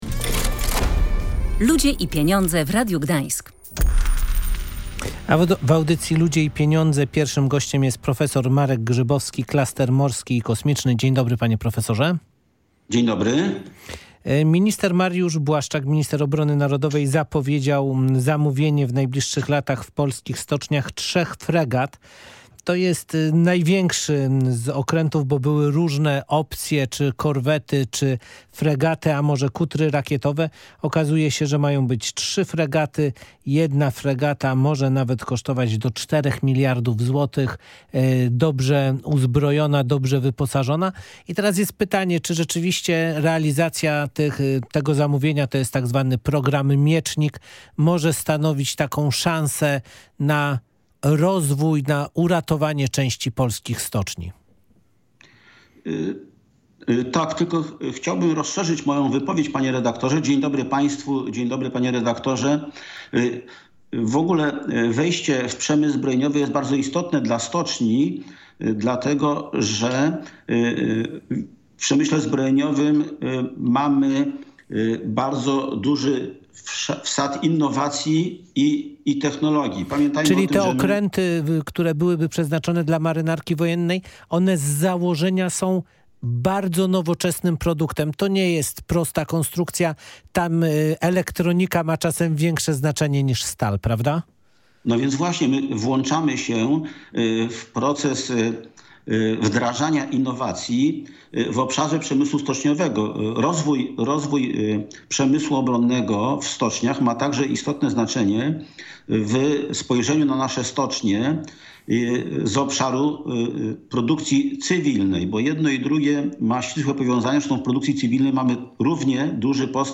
O inwestycjach w przemysł zbrojeniowy rozmawialiśmy